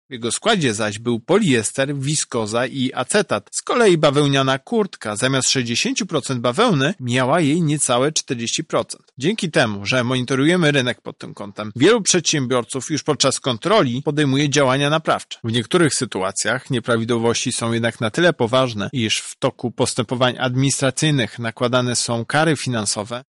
„Wełniany” garnitur, wełnę zawierał jedynie na etykiecie – mówi prezes UOKiK-u Tomasz Chróstny.